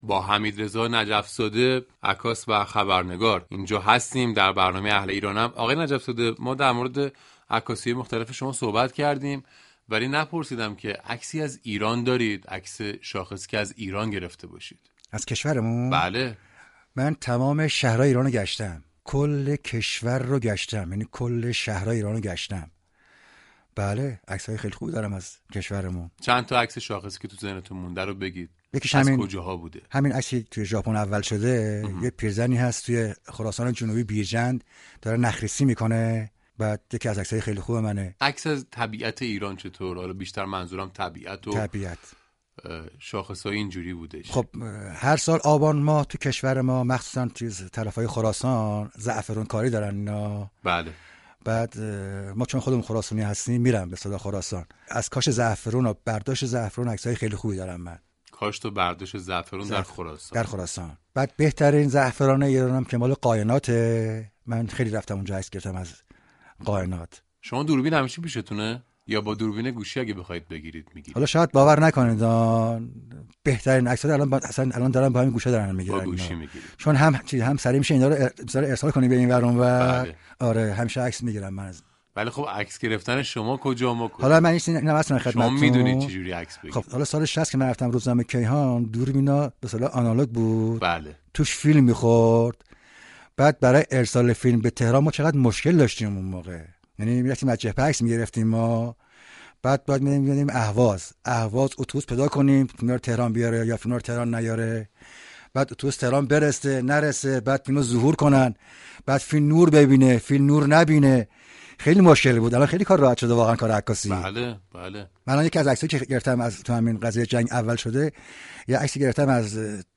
به گزارش روابط عمومی رادیو صبا ، «اهل ایرانم »عنوان یكی از ویژه برنامه های انتخاباتی این شكبه رادیویی است كه با گفت وگویی با اهالی هنر از جمله نقاش، خوشنویس، خواننده، آهنگساز و همچنین نخبگان و اهالی فرهنگ از انتخابات می گوید.